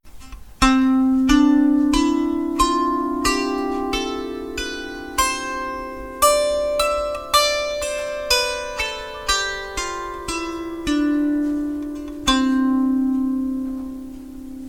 Die klassische Lyra ist ein kleines Harfeninstrument.
Die 10 Saiten sind in der C-Dur Tonleiter gestimmt.
Klangbeispiel Lyra
lyra-tonleiter.mp3